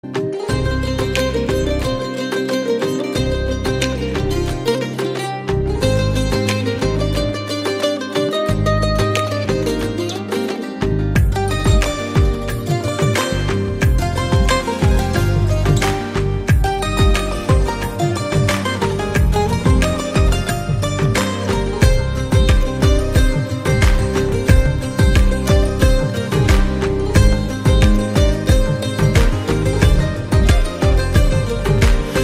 Category Instrumental